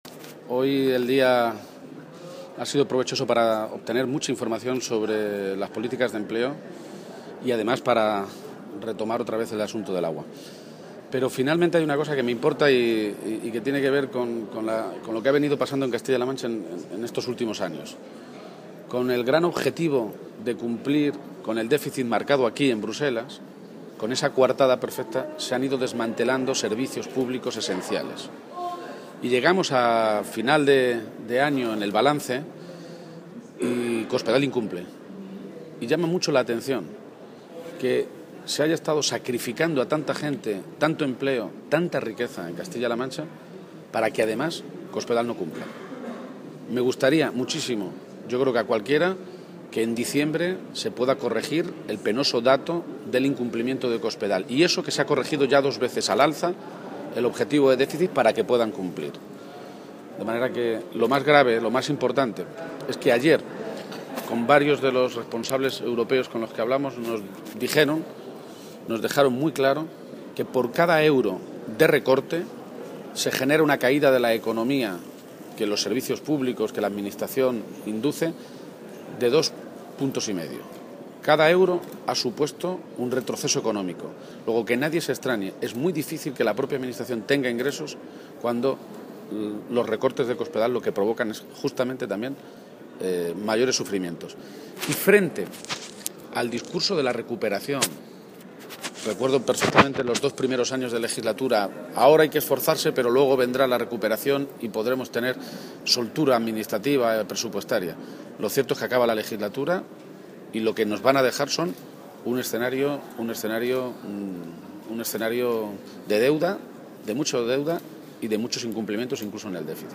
En declaraciones a los medios en Bruselas, donde ha asistido a la reunión del Grupo Socialista en el Parlamento Europeo, García-Page se ha referido al dato del déficit para destacar que «frente al discurso de la recuperación, lo cierto es que acaba la legislatura y lo que nos van a dejar es un escenario de deuda, mucha deuda, y muchos incumplimientos, incluso en el déficit».